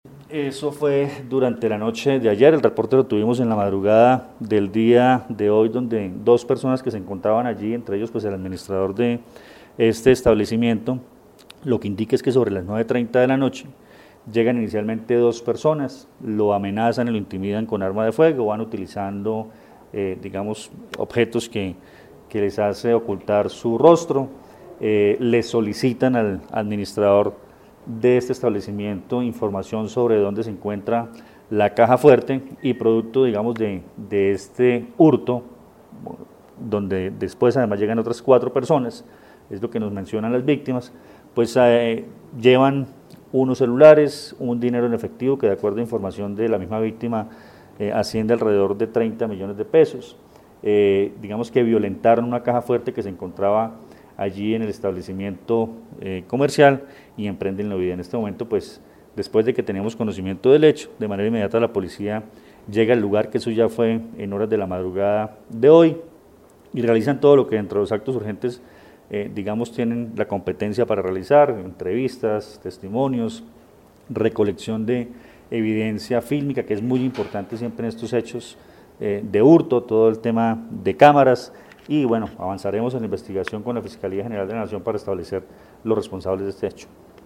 Coronel Luis Fernando Atuesta, comandante Policía del Quindío